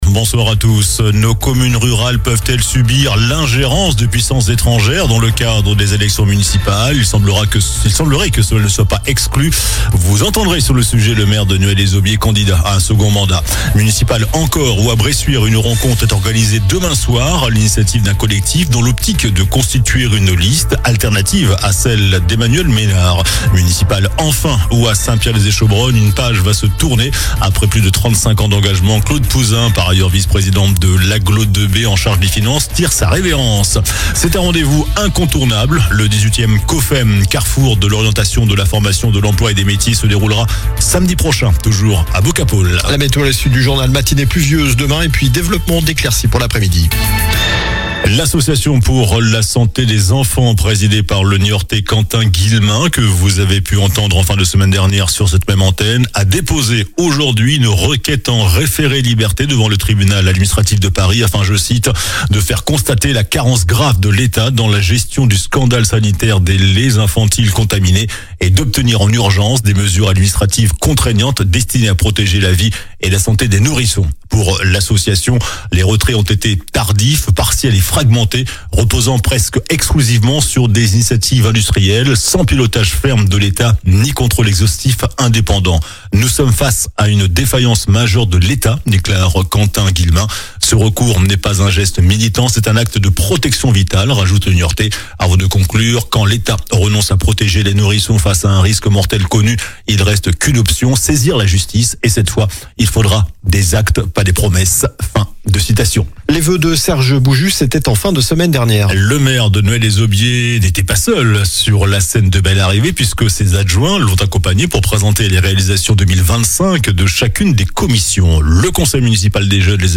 COLLINES LA RADIO : Réécoutez les flash infos et les différentes chroniques de votre radio⬦